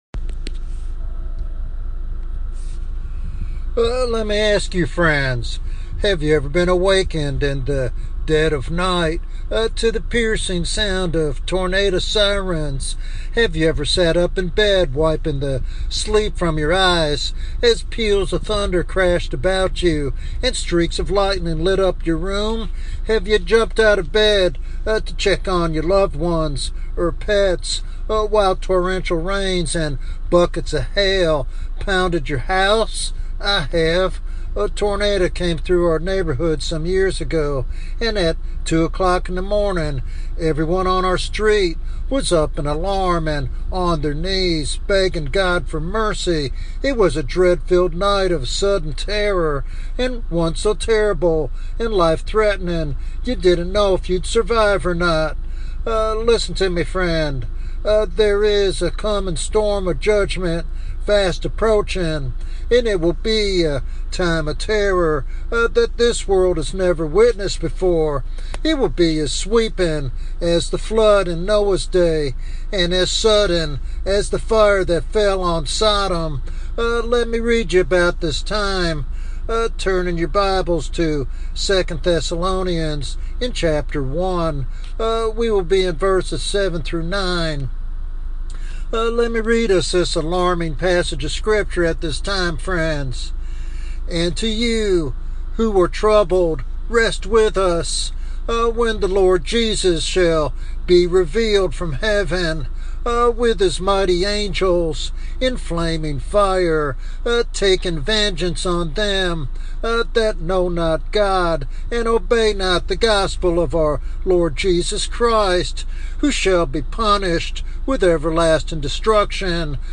In this prophetic sermon